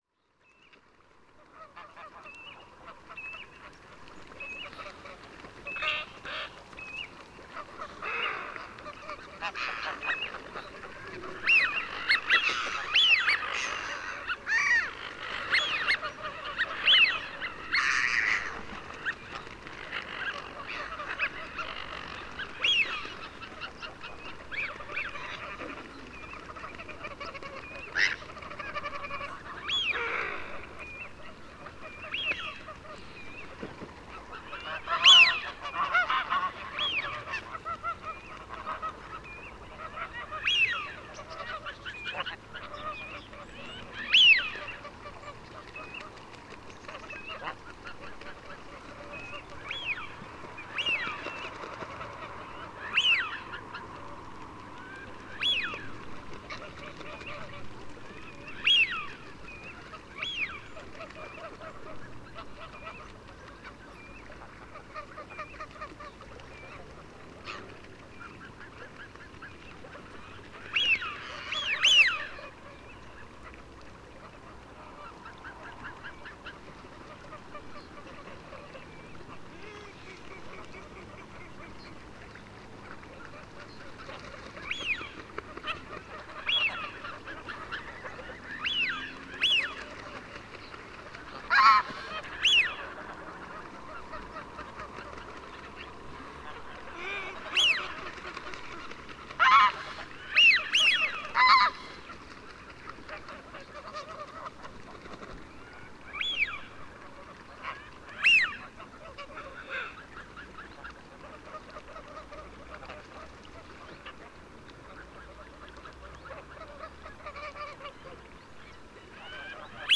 PFR09542, 1-10, 130925, Greylag Goose Anser anser, Mallard Anas platyrhynchos, Eurasian Wigeon Anas penolope, calls,
Texel, Netherlands, Telinga parabolic reflector